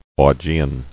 [Au·ge·an]